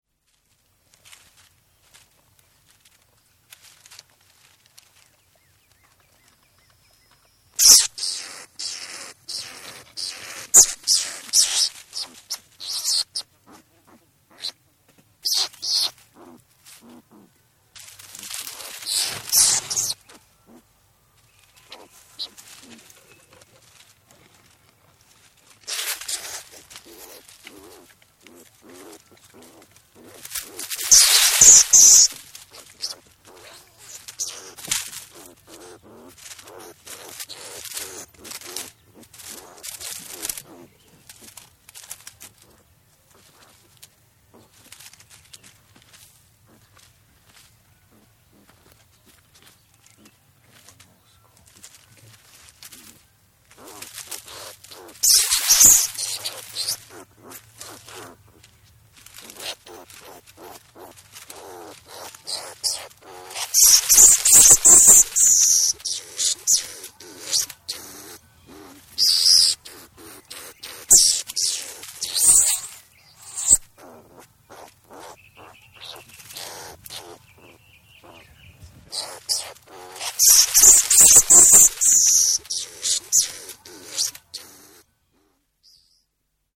Звуки скунса
Звук ярости и схватки скунса